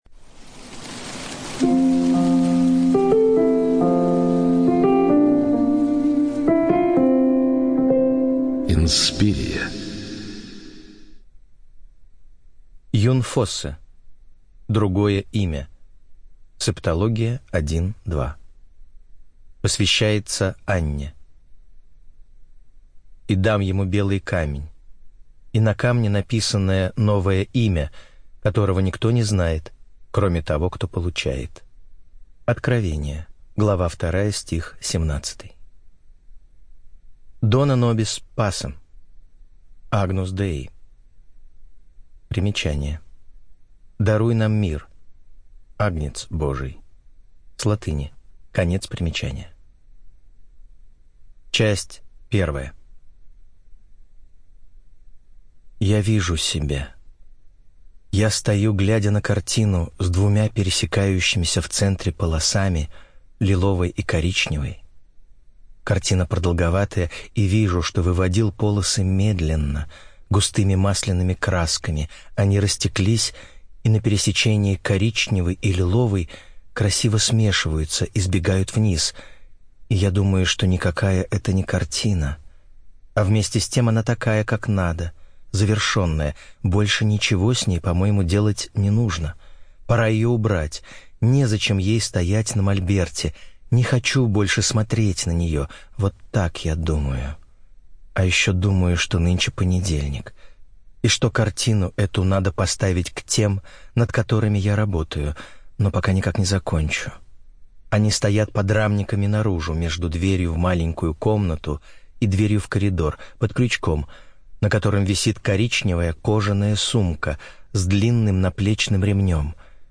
Студия звукозаписиInspiria